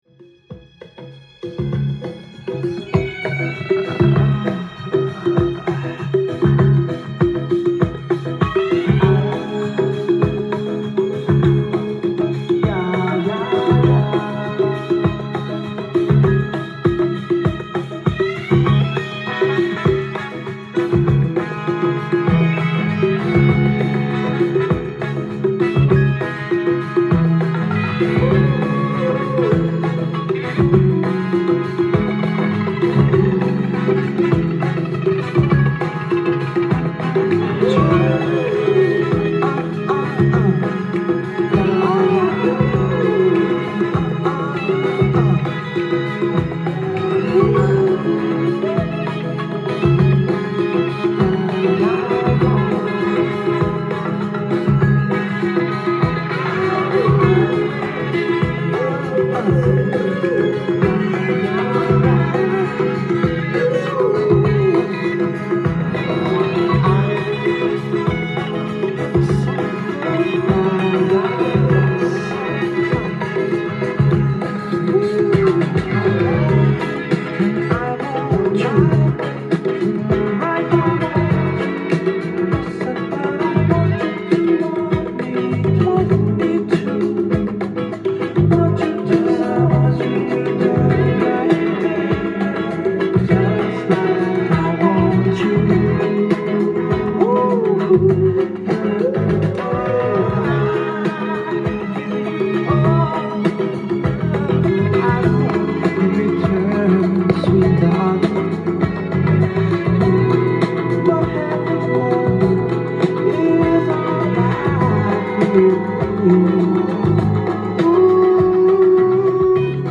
ジャンル：FUNK
店頭で録音した音源の為、多少の外部音や音質の悪さはございますが、サンプルとしてご視聴ください。